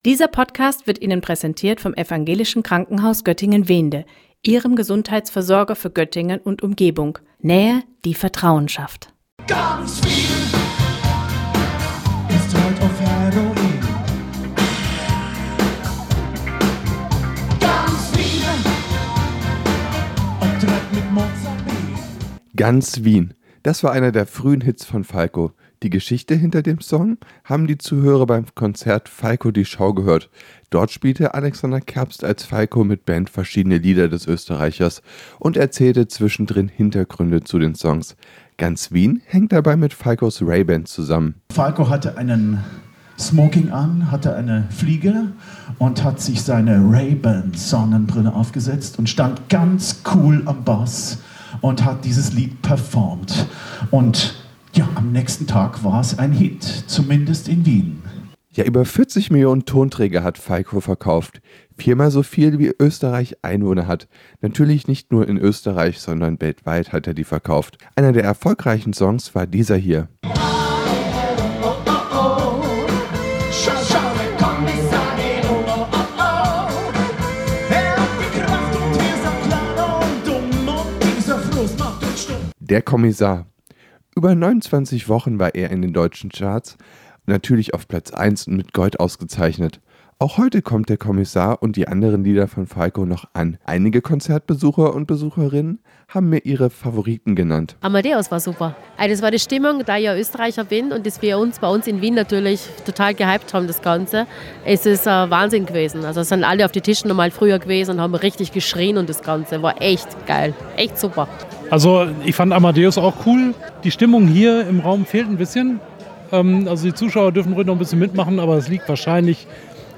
Ein Musiker muss bekannt genug sein, damit es eine Show in seinem Namen gibt – auch wenn der Künstler selbst schon tot ist. Eine solche Show gab es zum Beispiel zu Falco in Duderstadt.